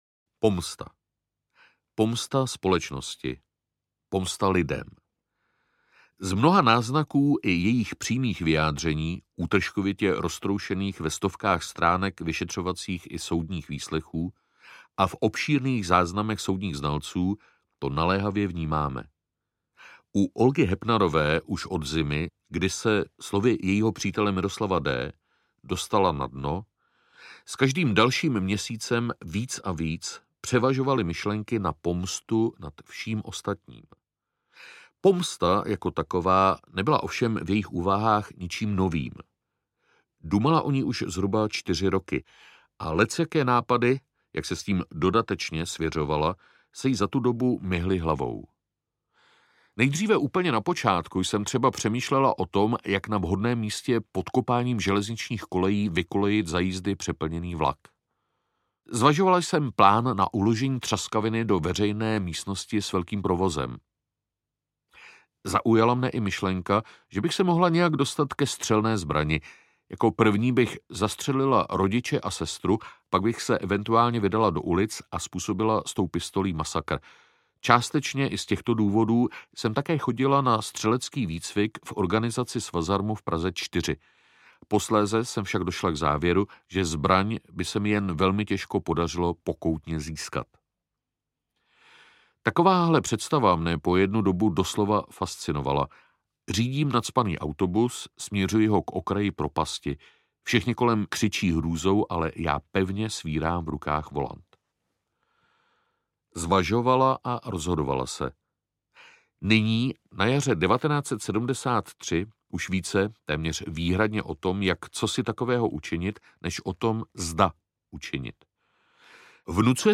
Oprátka za osm mrtvých audiokniha
Ukázka z knihy
Vyrobilo studio Soundguru.